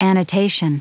Prononciation du mot annotation en anglais (fichier audio)
Prononciation du mot : annotation